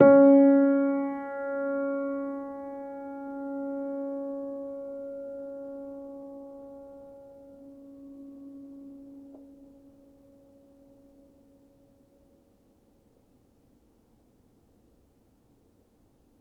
healing-soundscapes/Sound Banks/HSS_OP_Pack/Upright Piano/Player_dyn2_rr1_020.wav at 48f255e0b41e8171d9280be2389d1ef0a439d660